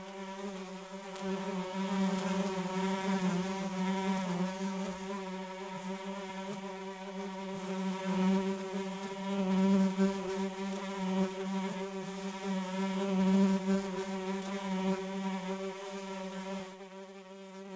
beyond/Assets/Sounds/Enemys/FlyBug/flys.ogg at main
flys.ogg